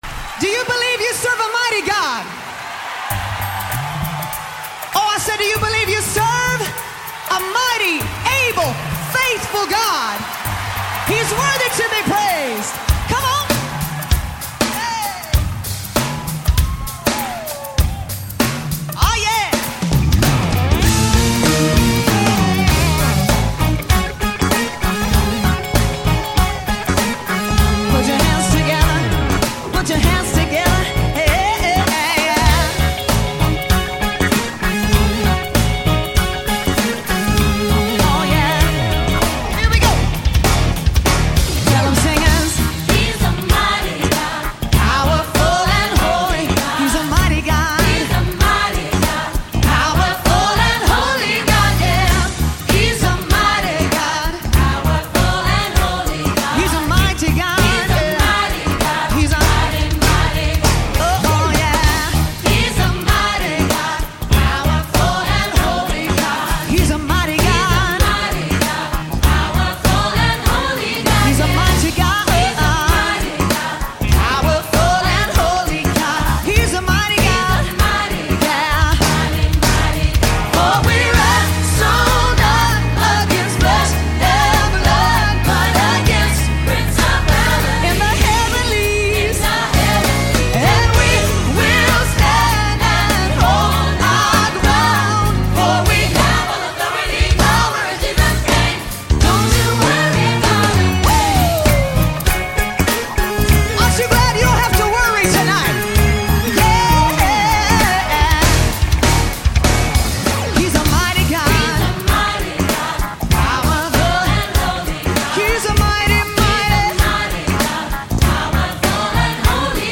239 просмотров 69 прослушиваний 1 скачиваний BPM: 98